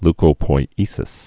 (lkō-poi-ēsĭs)